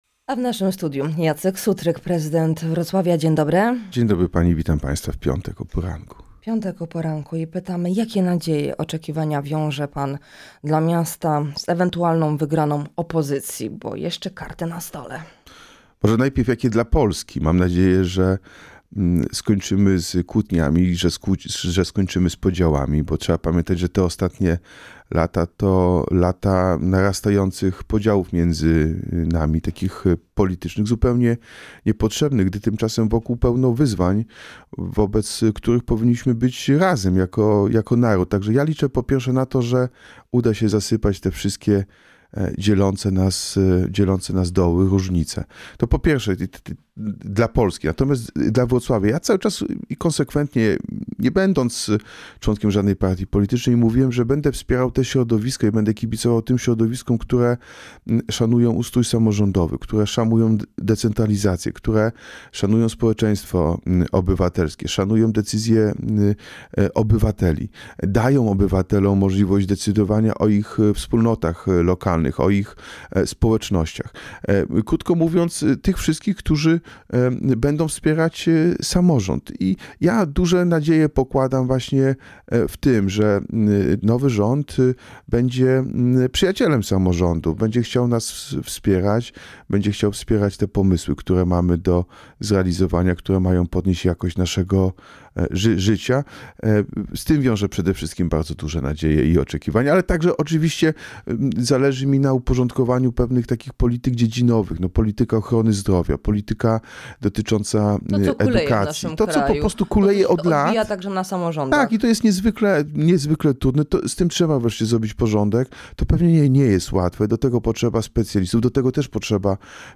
O oczekiwaniach po wyborach parlamentarnych, tramwaju na Jagodno, Zielonym Klinie, wizycie przewodniczącego PO Donalda Tuska we Wrocławiu, poparciu prezydenta w wyborach samorządowych, Dniu Niepodległości – rozmawiamy z prezydentem Wrocławia Jackiem Sutrykiem.